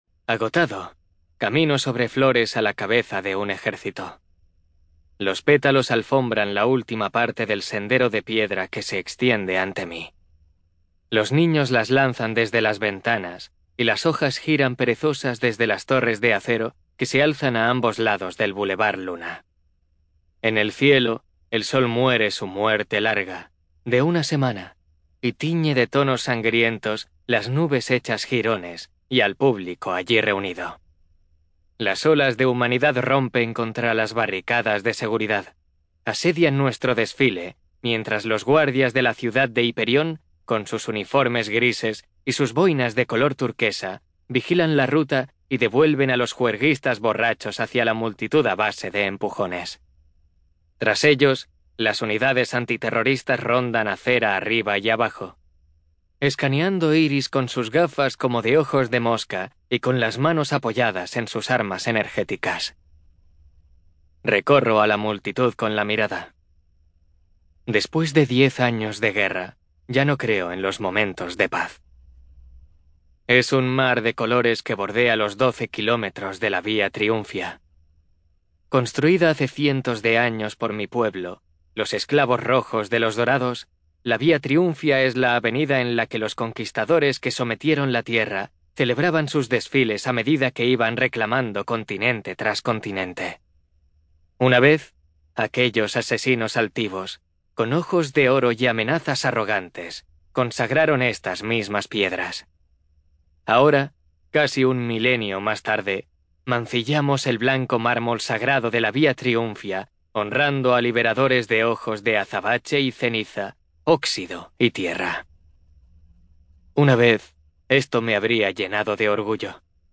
TIPO: Audiolibro CLIENTE: Audible Inc. ESTUDIO: Eclair Barcelona